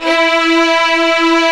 Index of /90_sSampleCDs/Roland LCDP13 String Sections/STR_Violins V/STR_Vls8 fff slo